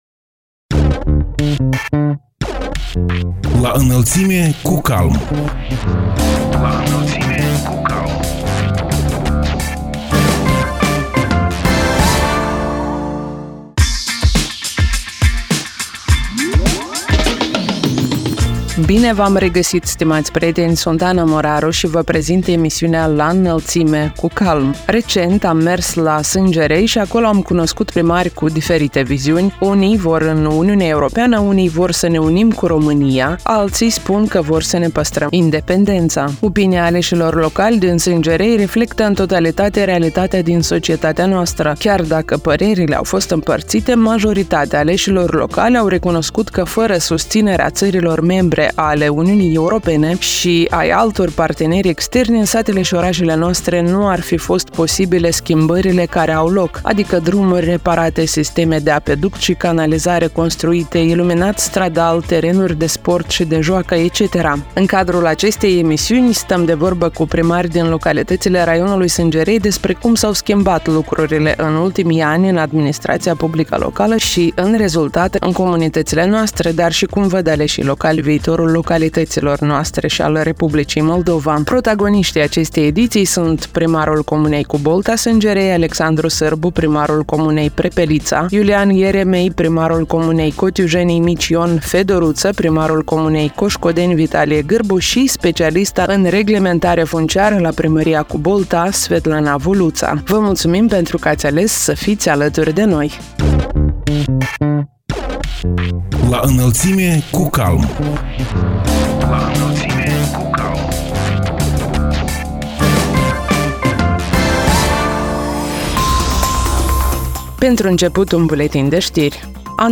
În cadrul emisiunii „La Înălțime cu CALM” stăm de vorbă cu primari din localitățile raionului Sîngerei despre cum s-au schimbat lucrurile în ultimii ani în administrația publică locală și, în rezultat, în comunitățile noastre, dar și cum văd aleșii locali viitorul localităților noastre și al Republicii Moldova.